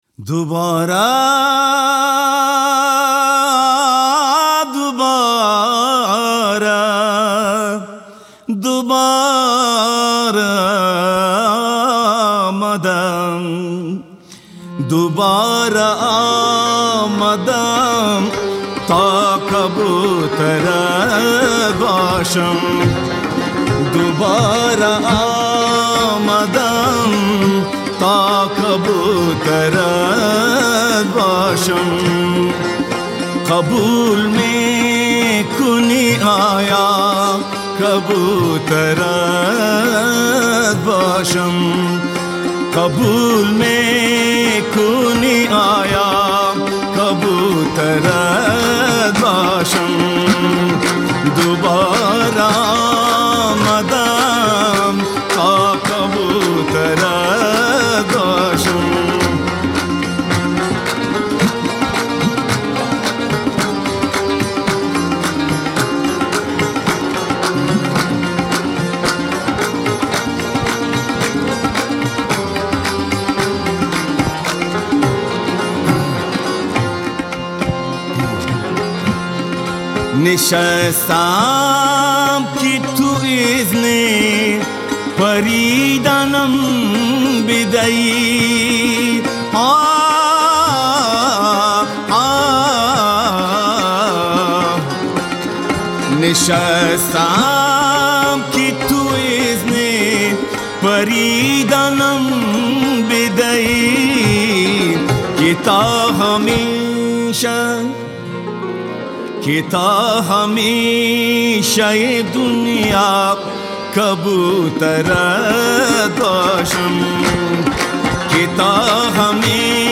برگرفته از قوالی ها و مناقب خوانی مکتب هرات (افغانستان)